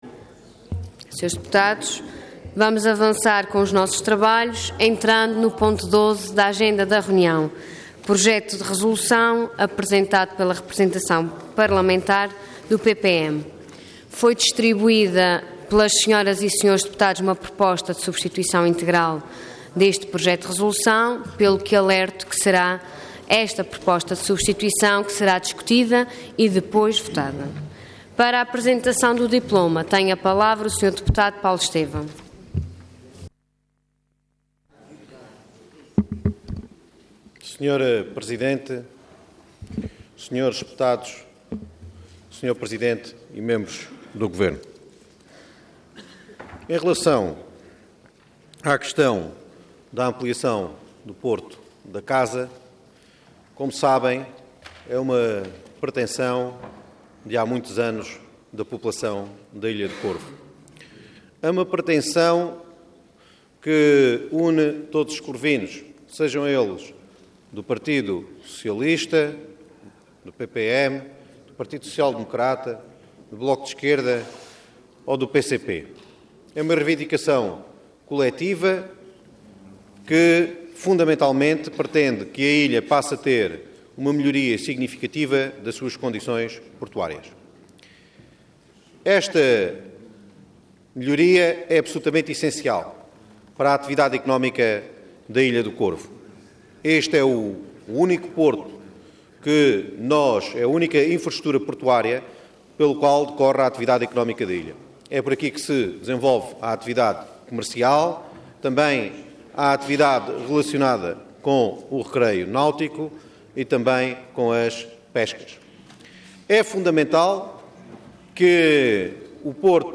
Intervenção Projeto de Resolução Orador Paulo Estêvão Cargo Deputado Entidade PPM